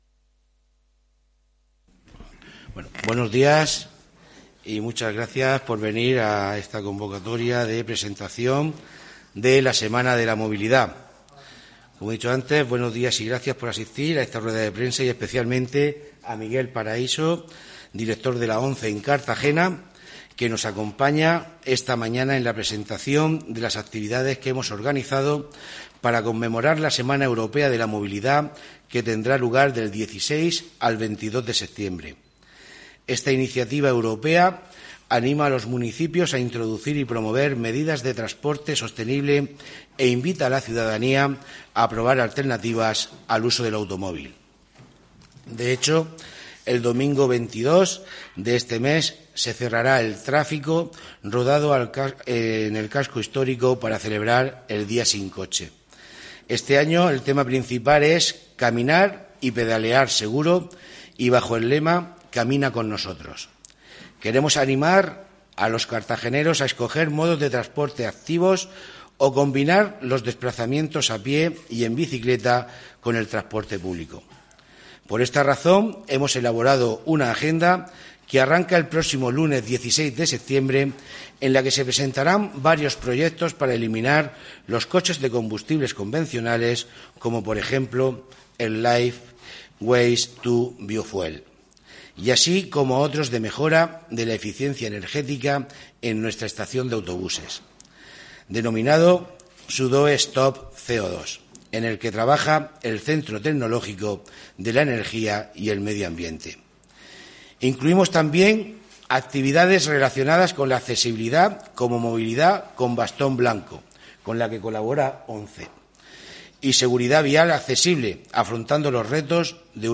Audio: Presentaci�n Semana de la Movilidad (MP3 - 6,17 MB)